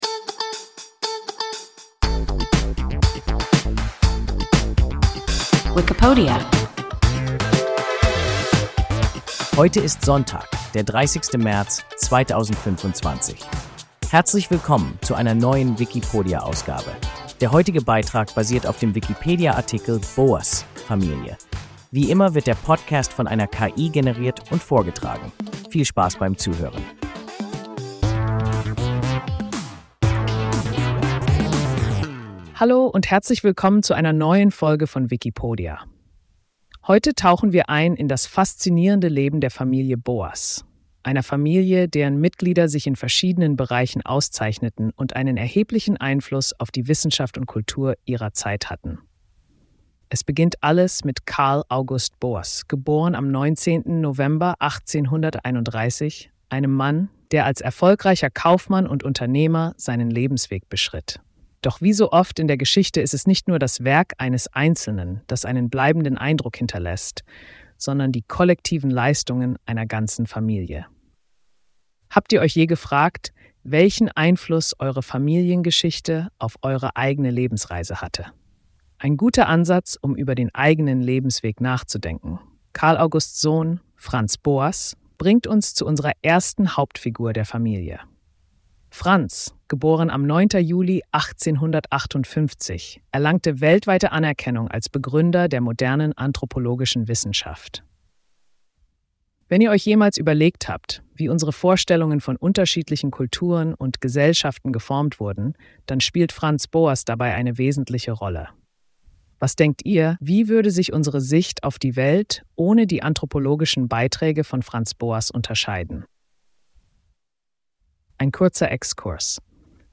Boas (Familie) – WIKIPODIA – ein KI Podcast